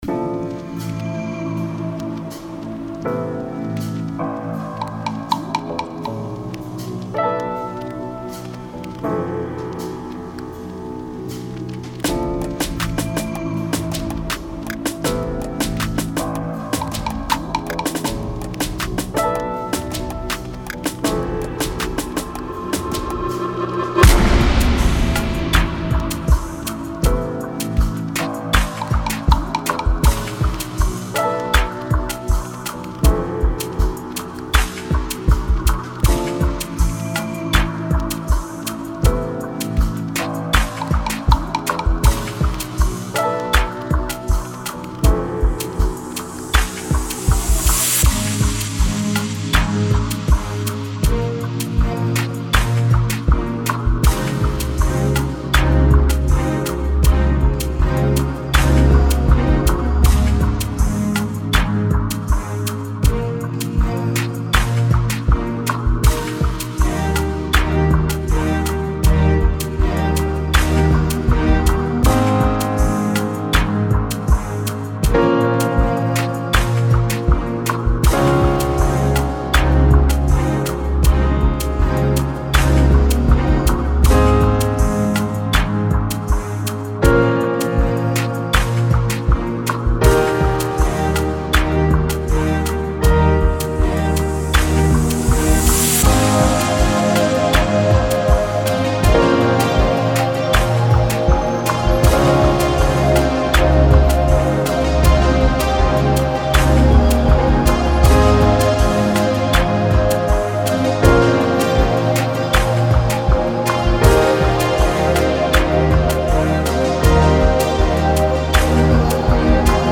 Expect vibe changes.
Tempo 80BPM (Andante)
Genre Slow Energy Amapiano
Type Ad-libstrumental
Mood energetic to Chill